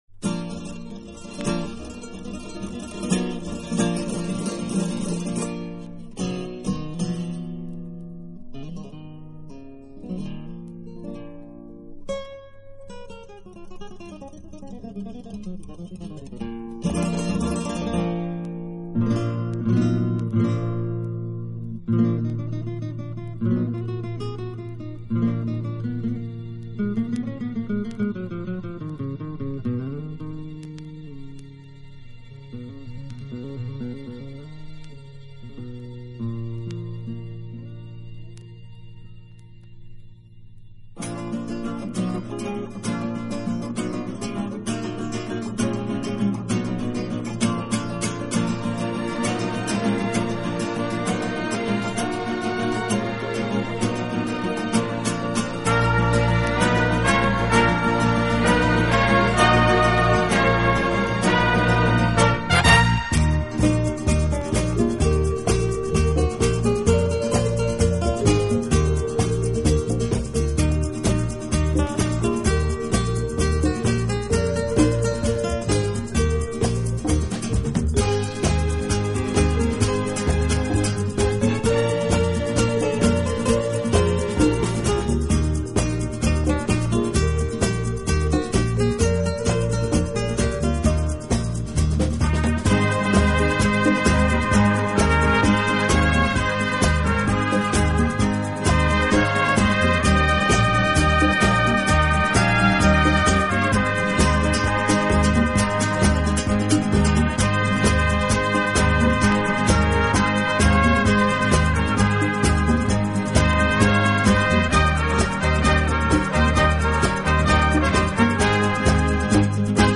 演奏轻柔优美，特別是打击乐器的演奏，具有拉美音乐独特的韵味。